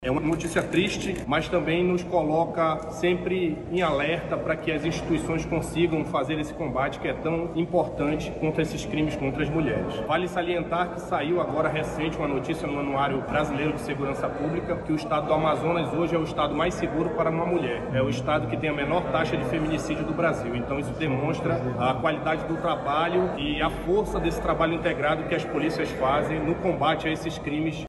Durante uma entrevista coletiva, o subcomandante-geral da Polícia Militar do Amazonas – PMAM, coronel Thiago Balbi, disse que o Estado é um dos mais seguros do país para as mulheres.